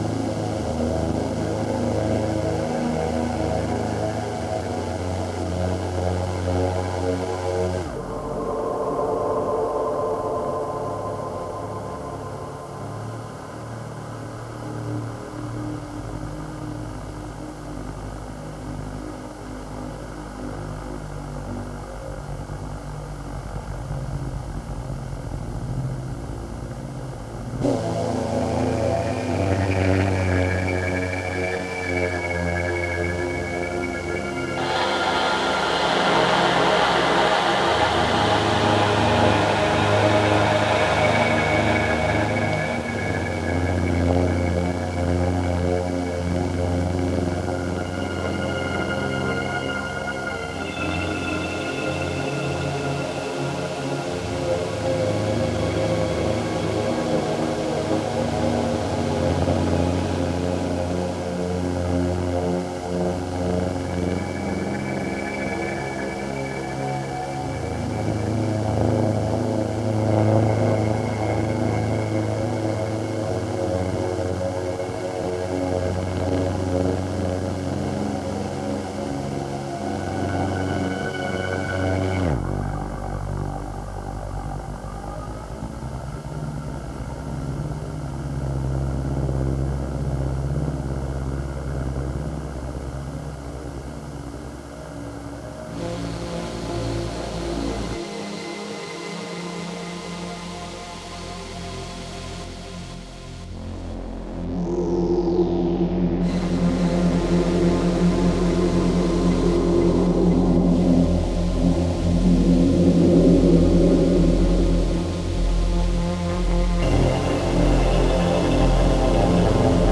Industrial bleakness and cold rhythms
• Genre: Industrial